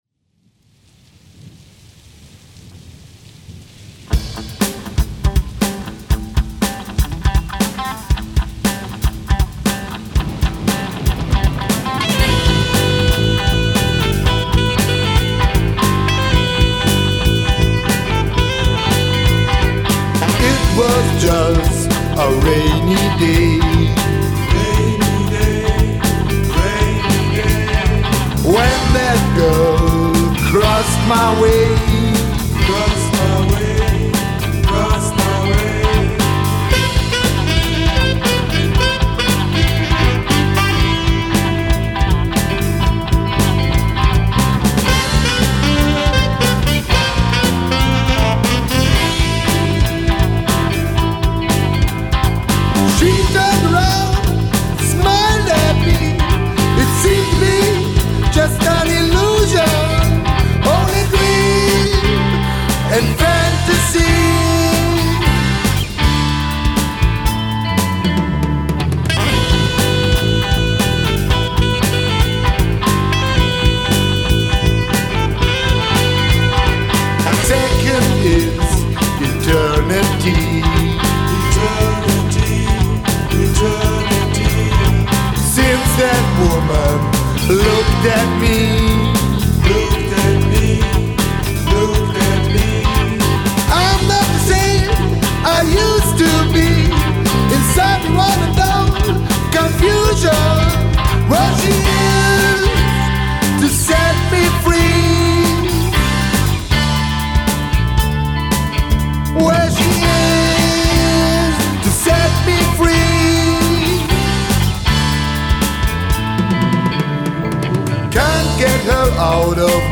Diese Produktion wurde analog auf 16 Spuren recorded und analog gemischt. In der 2019er-Version wurde nur leicht geschnitten und etwas Piano ergänzt.
Gesang
Gitarre
Saxofon
Bass, add.Keys&Perc
Drums